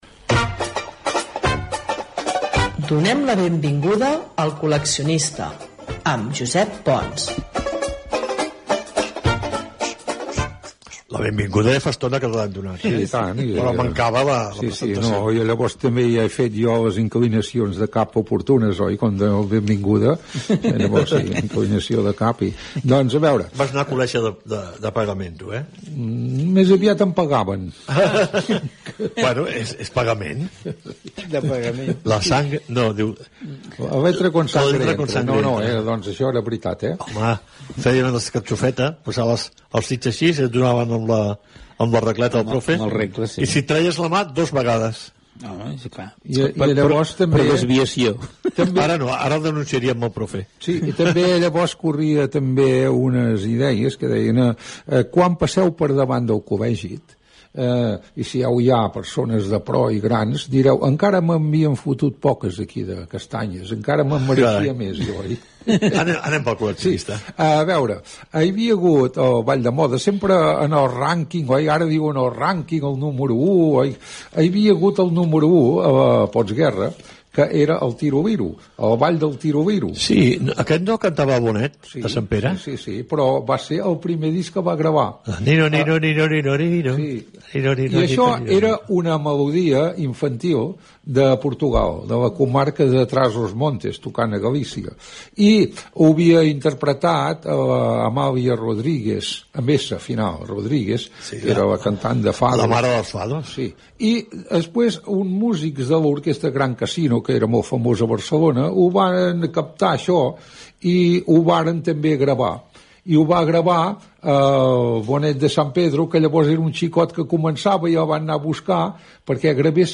El ball de "El Tiroliro" de 1941, Careta de sortida de l'espai.
Entreteniment
FM